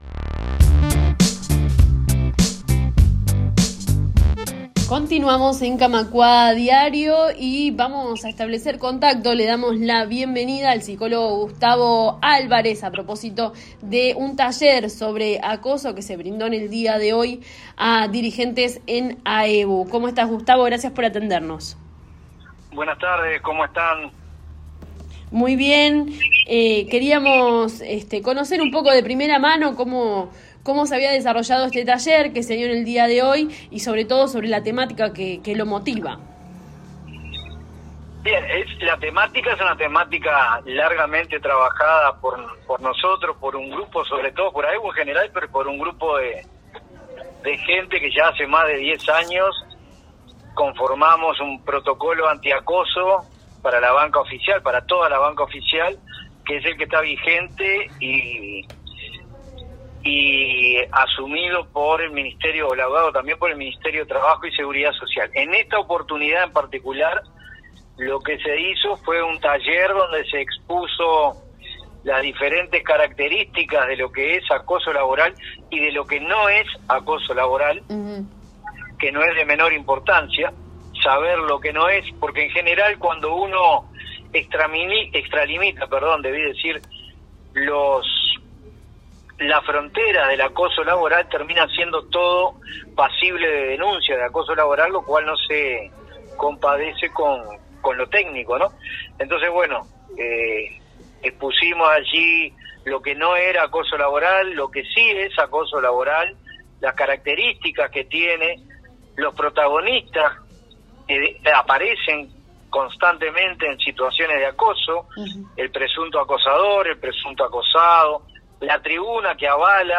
dialogaron con Camacuá y Reconquista